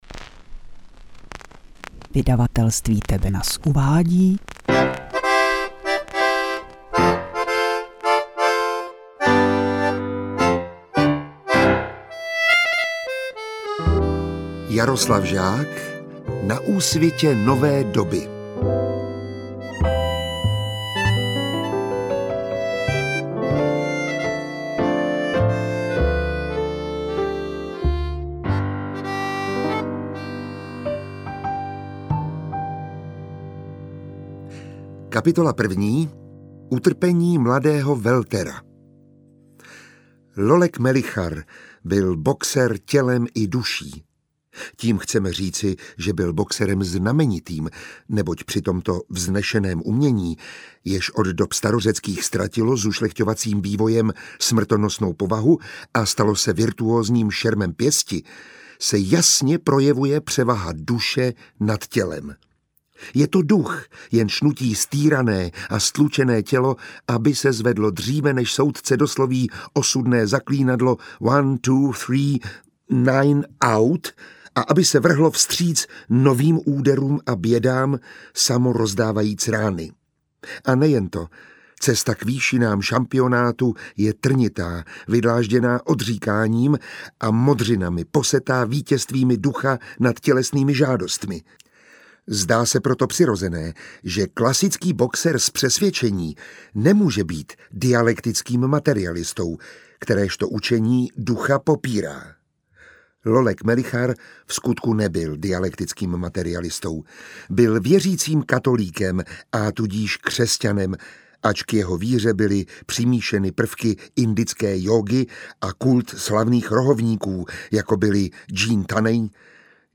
Radioservis představuje audio knižní novinku Jaroslav Žák: NA ÚSVITĚ NOVÉ DOBY
Jaroslav Žák: NA ÚSVITĚ NOVÉ DOBY – čte Miroslav Táborský